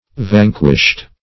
Vanquished (v[a^][ng]"kw[i^]sht); p. pr.